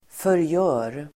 Uttal: [förj'ö:r]